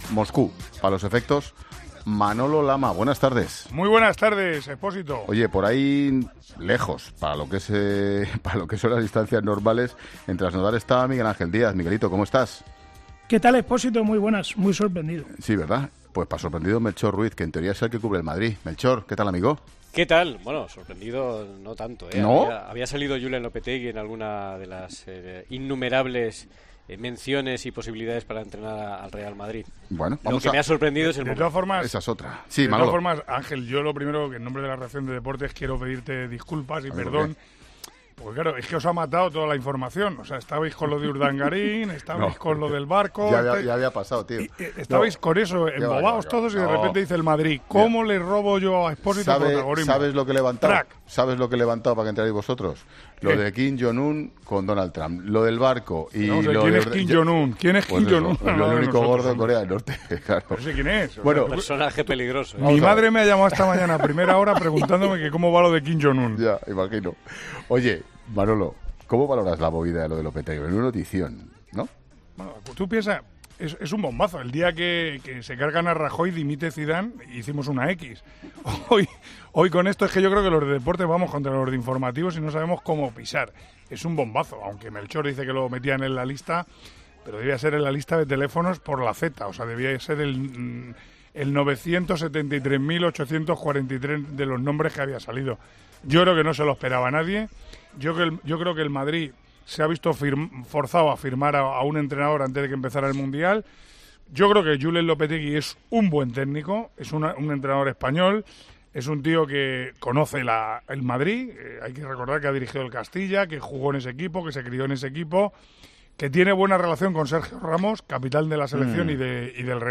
AUDIO: Escucha las primeras reacciones a la noticia de Manolo Lama, Miguel Ángel Díaz y Melchor Ruiz, en La Tarde de COPE.